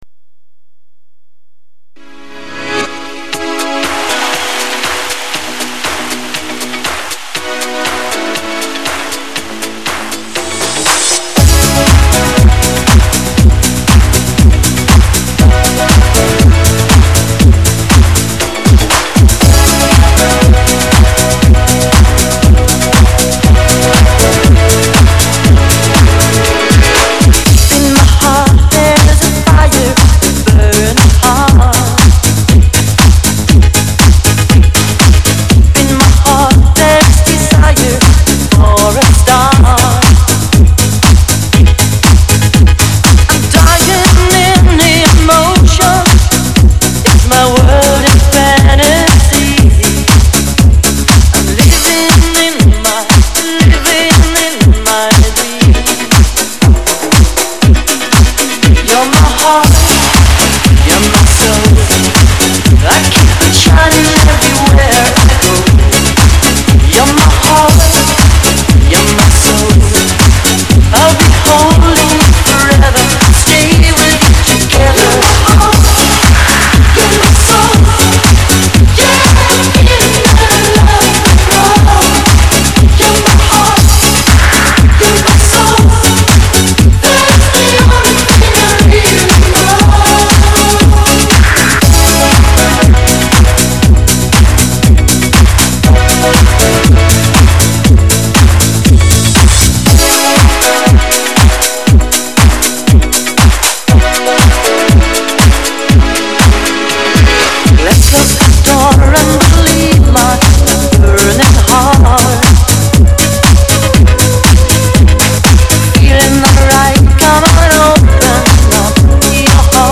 [2004-12-17][分享]来点怀旧的动感舞曲